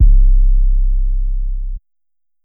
808 (Portland).wav